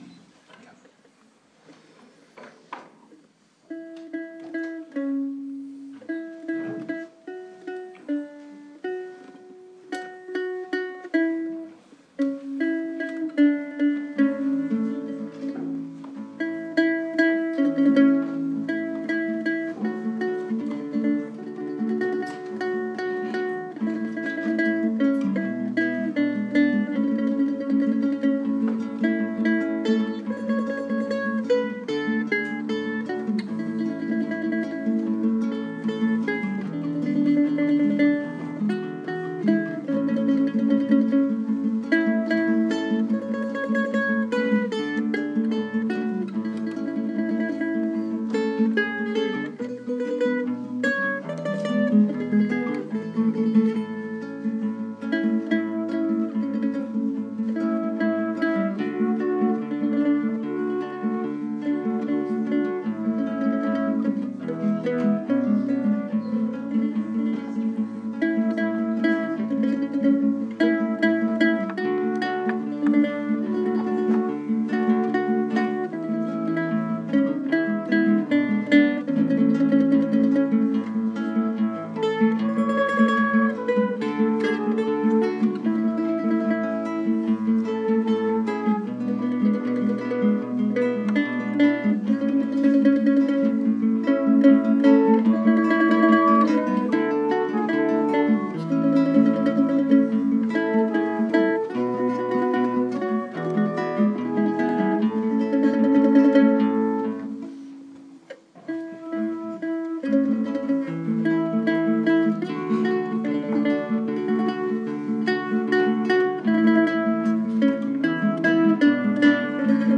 Instrumental during communion